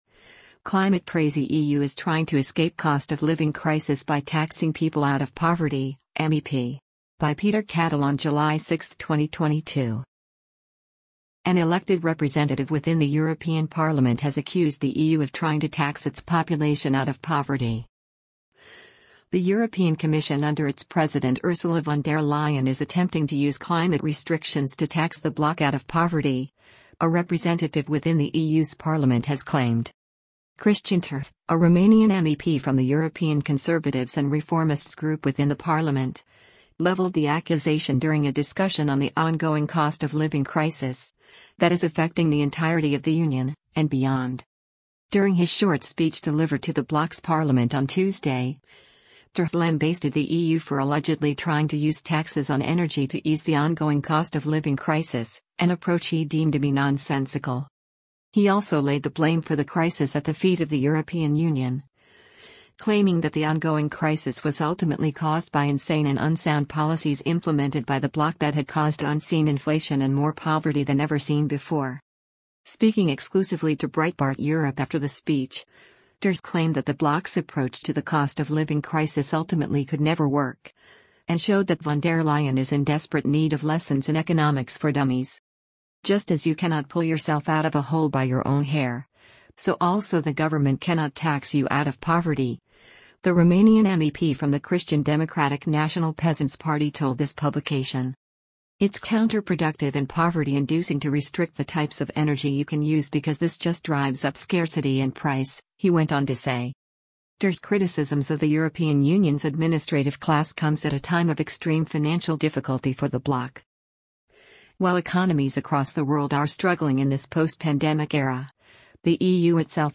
During his short speech delivered to the bloc’s parliament on Tuesday, Terhes lambasted the EU for allegedly trying to use taxes on energy to ease the ongoing cost of living crisis, an approach he deemed to be nonsensical.